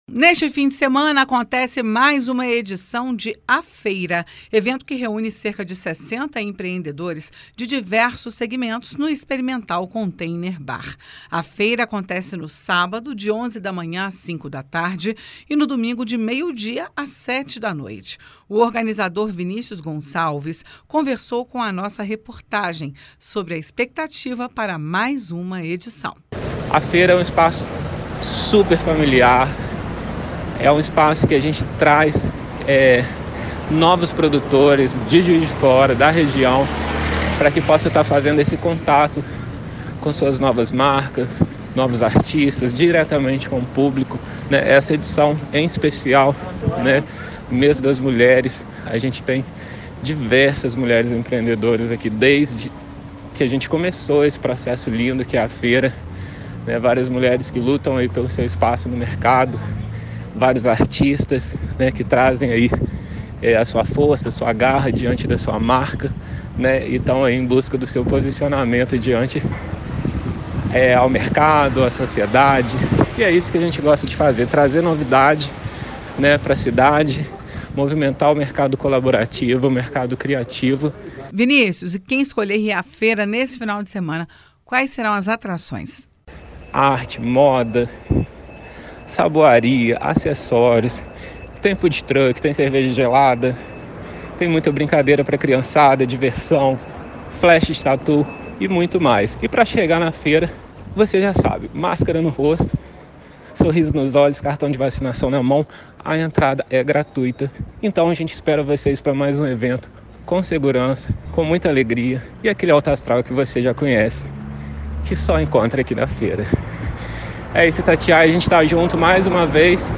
AFeira: Evento movimenta empreendedores em Juiz de Fora.